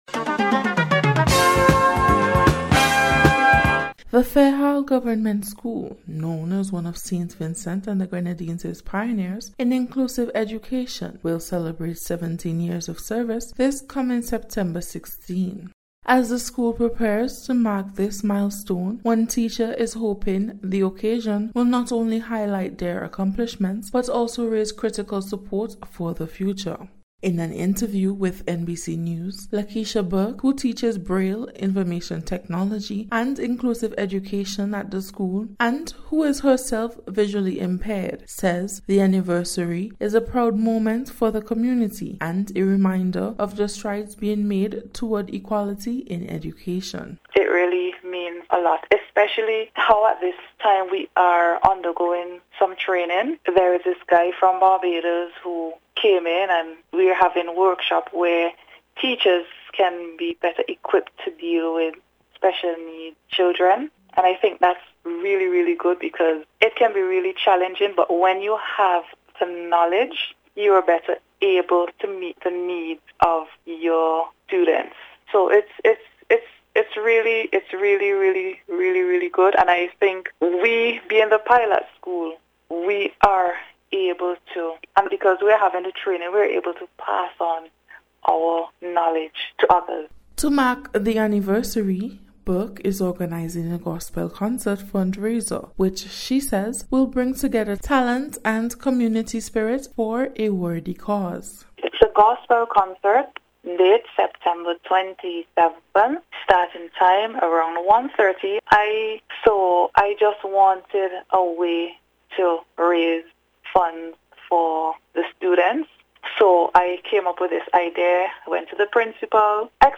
NBC’s Special Report-Tuesday 22nd July,2025